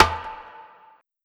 Snare (Fireworks).wav